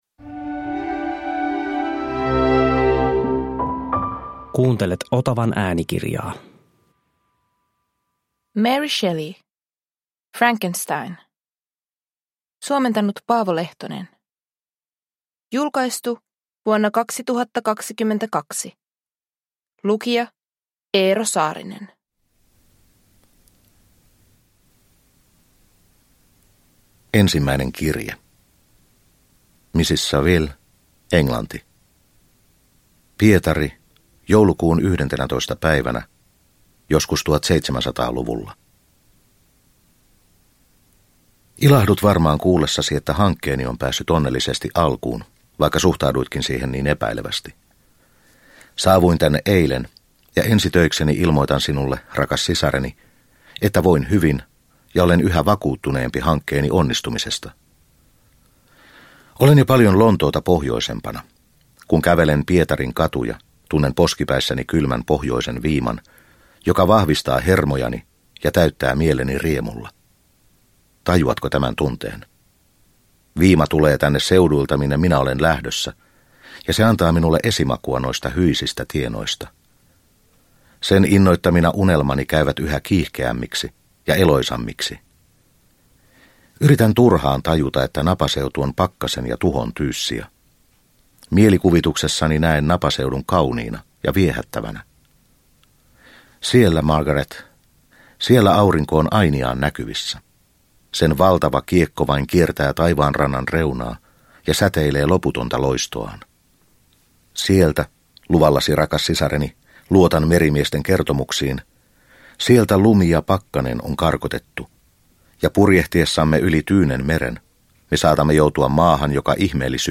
Frankenstein – Ljudbok – Laddas ner